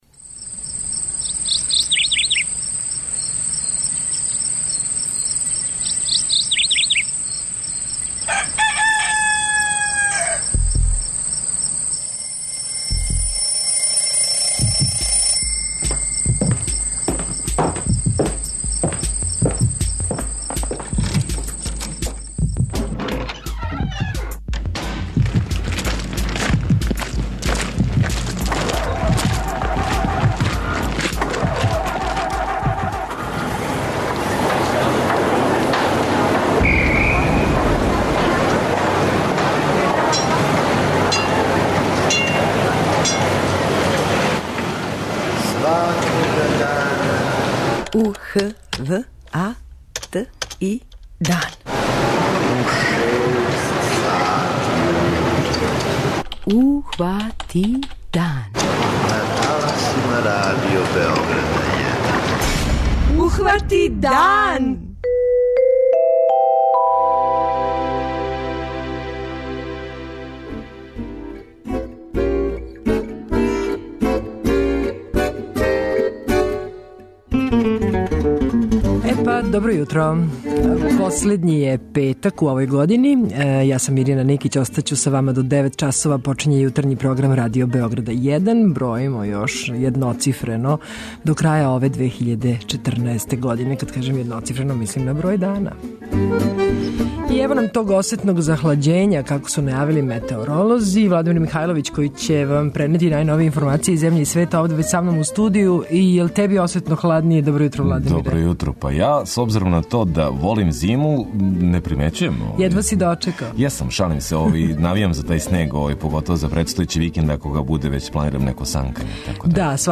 Из садржаја Јутарњег програма издвајамо: